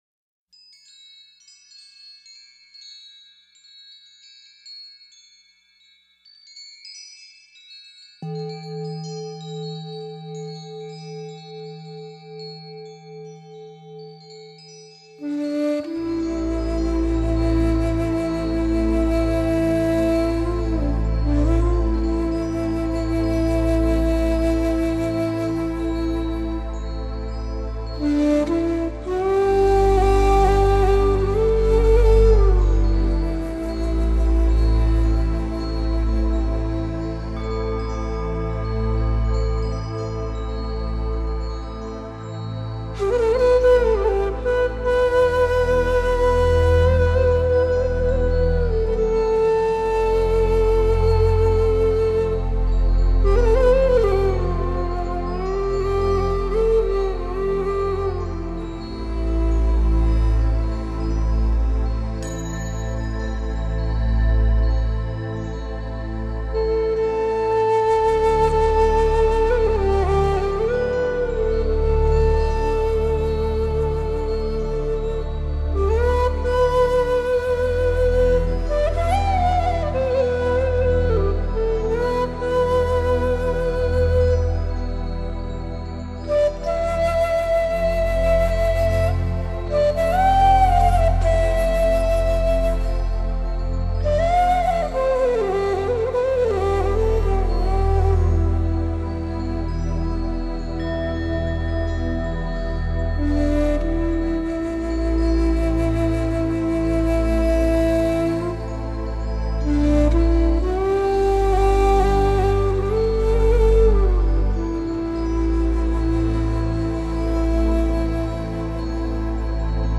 新世纪冥想类